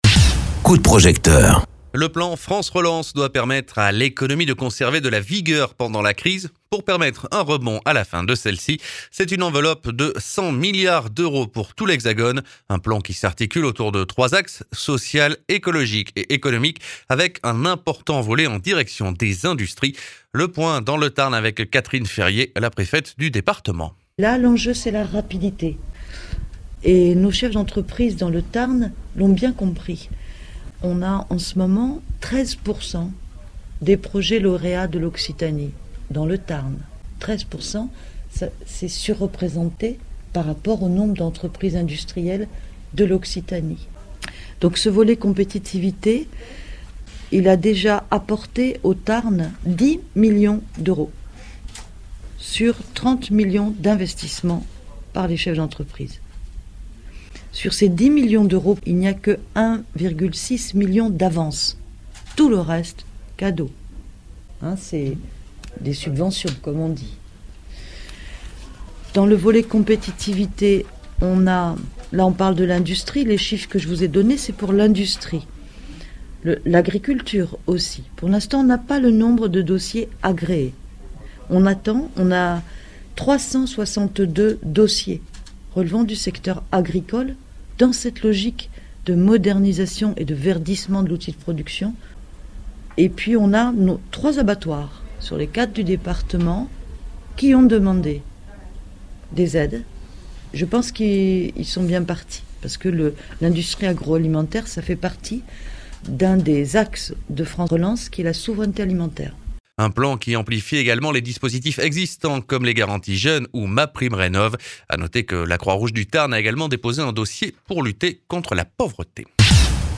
Interviews
Invité(s) : Catherine Ferrier, préfète du Tarn